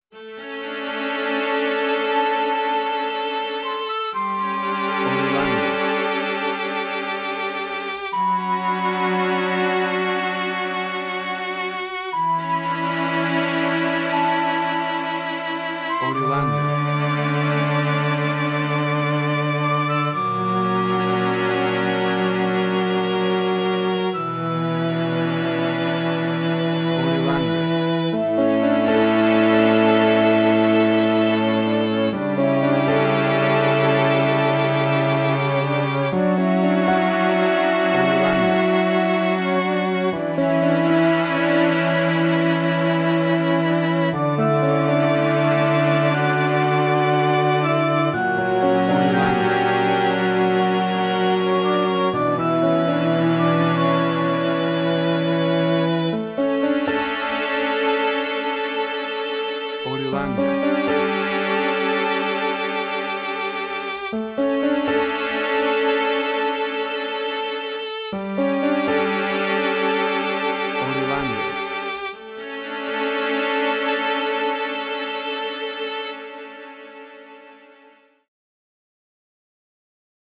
WAV Sample Rate: 16-Bit stereo, 44.1 kHz